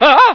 lots of screaming scientists
scream17.ogg